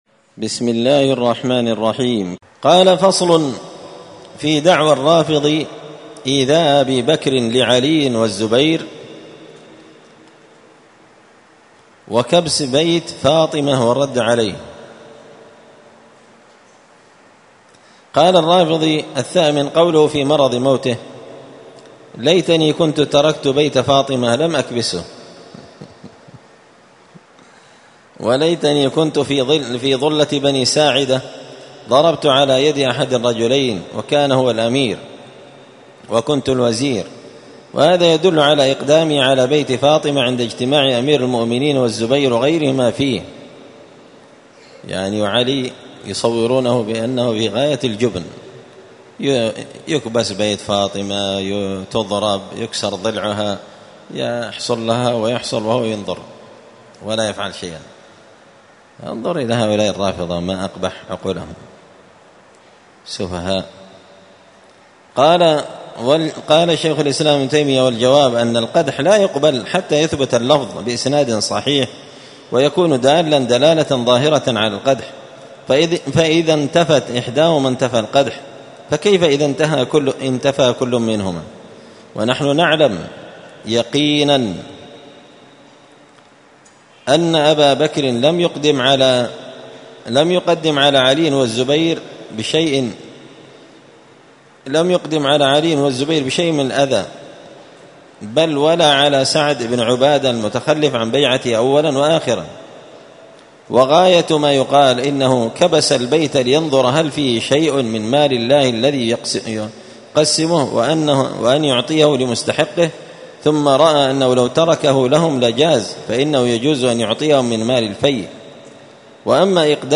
الأربعاء 12 ربيع الأول 1445 هــــ | الدروس، دروس الردود، مختصر منهاج السنة النبوية لشيخ الإسلام ابن تيمية | شارك بتعليقك | 63 المشاهدات